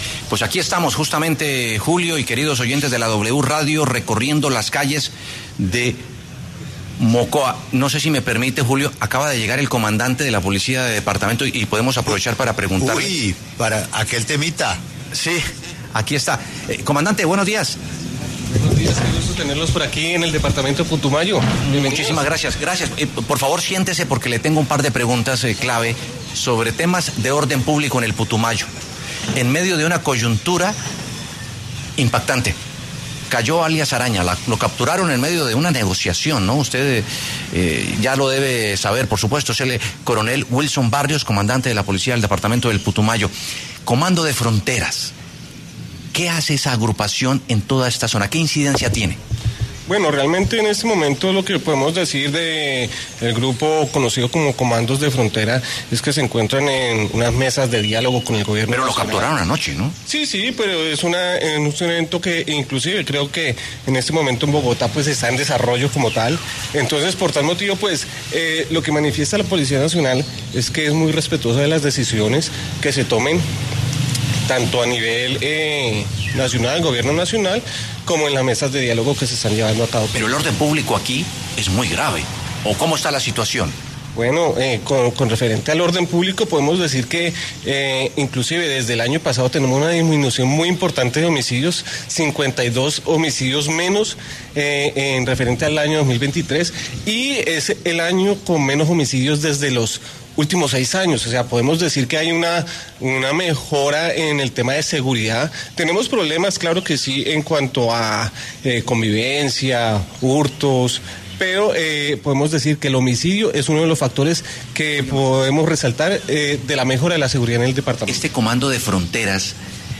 El comandante de la Policía en el departamento de Putumayo, Wilson Barrios, conversó con La W sobre la situación de orden público con las disidencias Farc del Comando de Frontera.